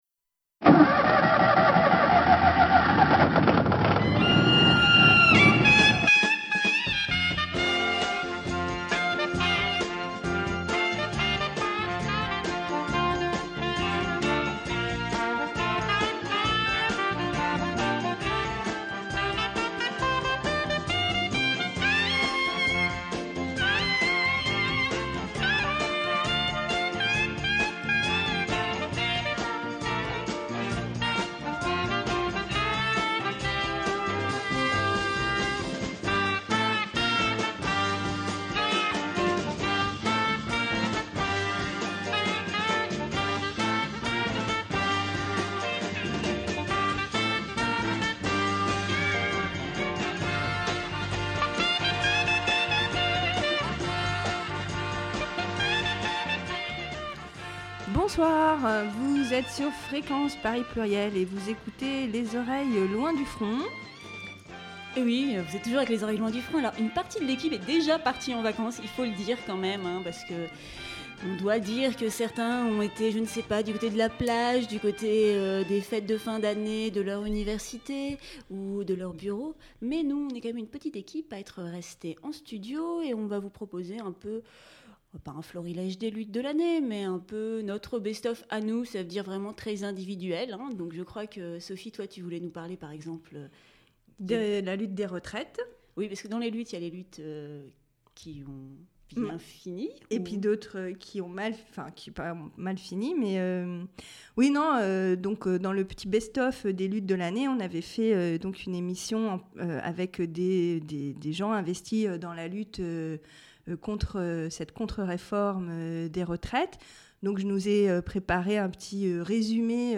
Une émission patchwork pour clore la saison 2010-2011 des Oreilles Loin Du Front, avec un retour sur quelques évènements et luttes de l’année écoulée : la lutte de l’automne 2010 contre la réforme des retraites, la révolution tunisienne, la dénonciation de l’ambiance raciste et anti-roms depuis l’été dernier.
au téléphone